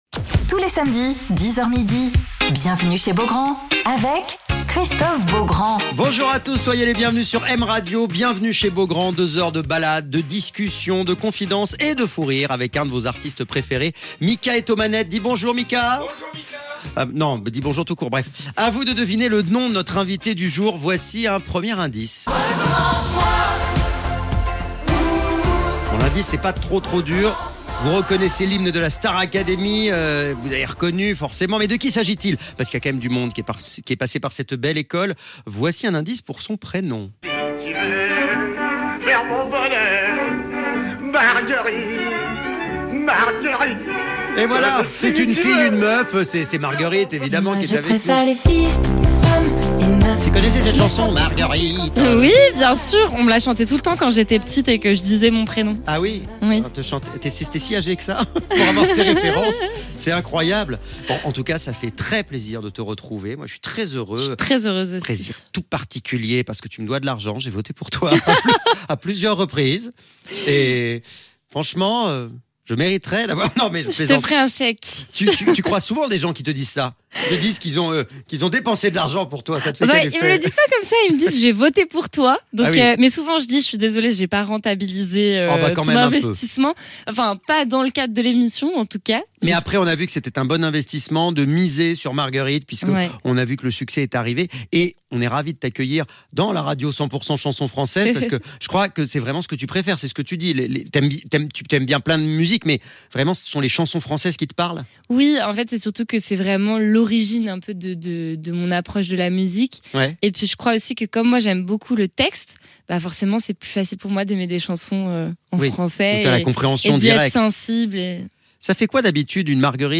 Alors qu'elle prépare sa tournée avec une première date le 24 mars à la Cigale, à Paris, Marguerite est l'invitée de Christophe Beaugrand sur M Radio !